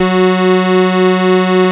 OSCAR 1  F#4.wav